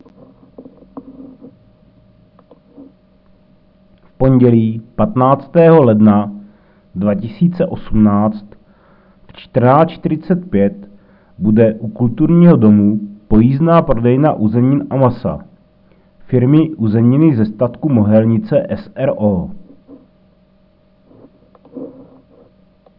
Hlášení 14.1. a 15.1.2018 pojízdná prodejna uzenin a masa